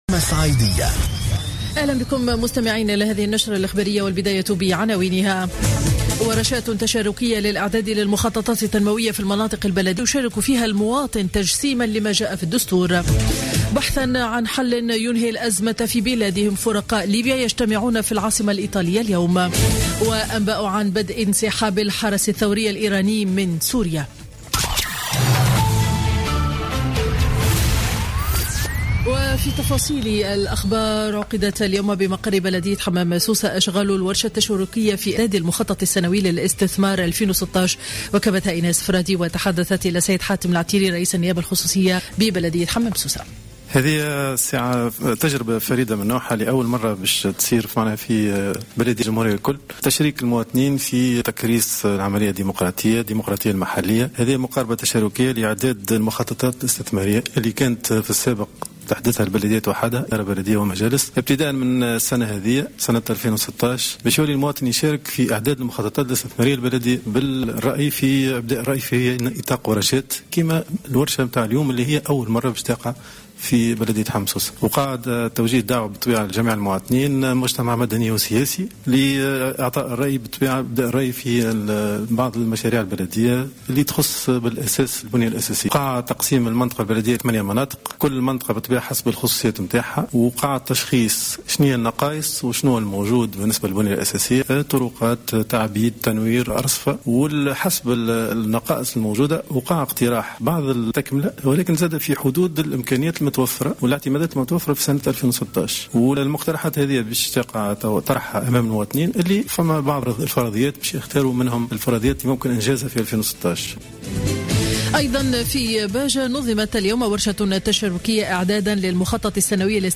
نشرة أخبار منتصف النهار ليوم الأحد 13 ديسمبر 2015